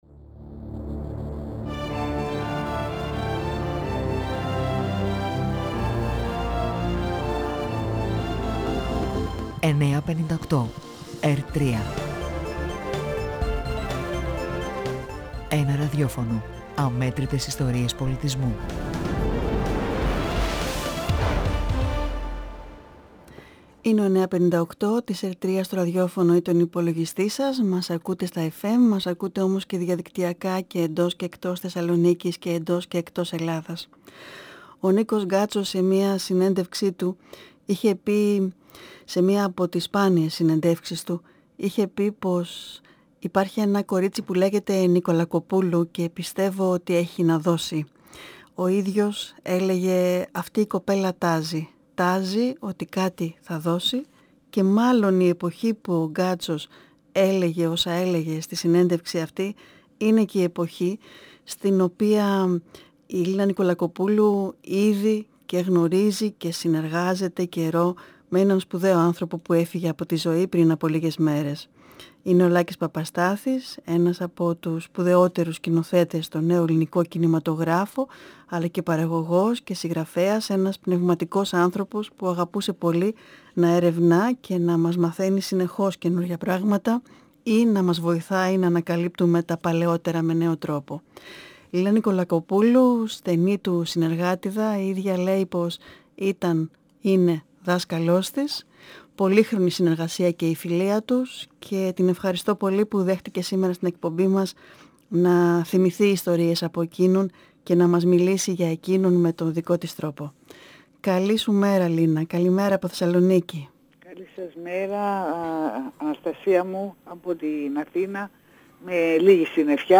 Η Λίνα Νικολακοπούλου μιλά για τον Λάκη Παπαστάθη, τον δάσκαλό της, για το έργο και την προσωπικότητά του. Η συνέντευξη πραγματοποιήθηκε τη Δευτέρα 13/3/2023 Ο Λάκης Παπαστάθης (1943 – 8 Μαρτίου 2023) γεννήθηκε στο Βόλο.